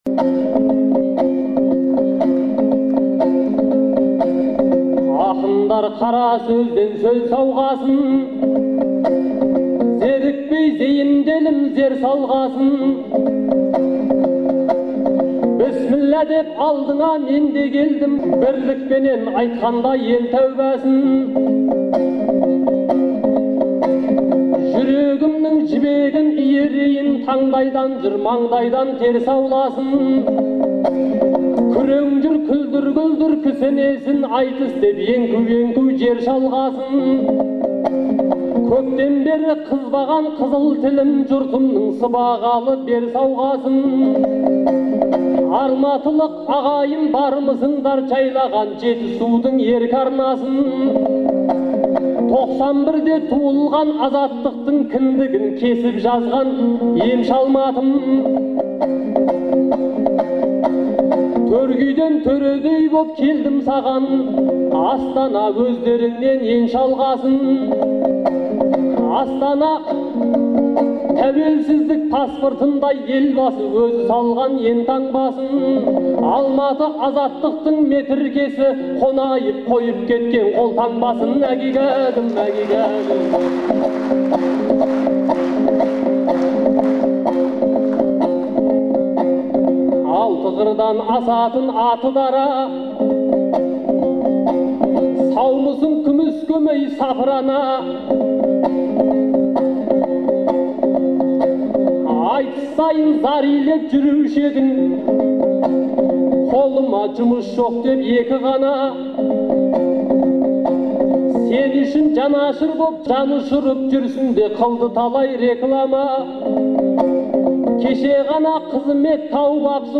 2011 жылы мамырдың 2-сі күні Алматыда өткен «Төртеу түгел болса» атты айтыстың төртінші жұбы